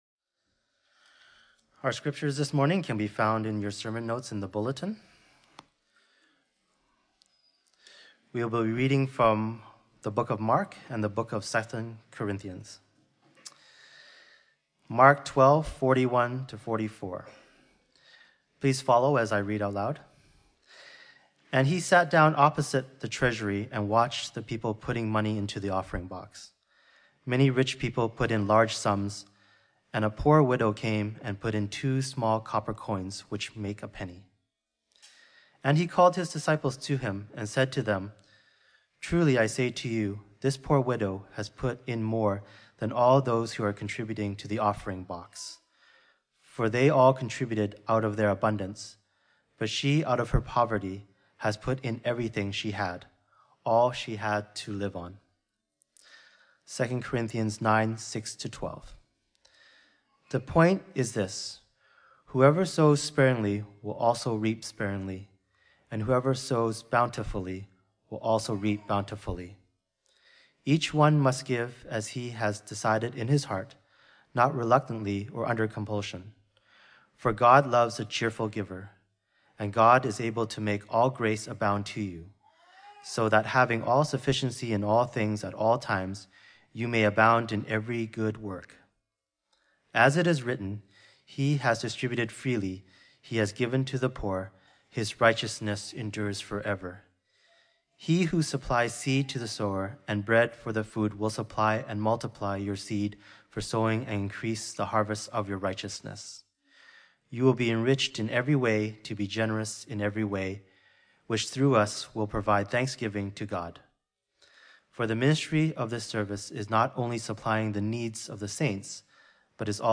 Fraser Lands Church Worship Service & Sermon Podcast | Fraser Lands Church